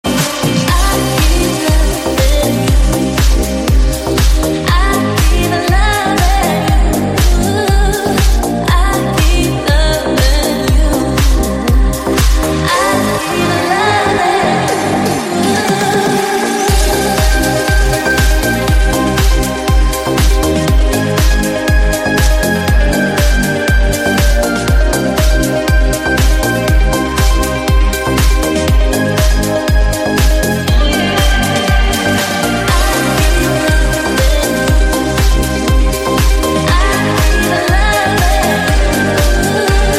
deep house
Жанр: Deep House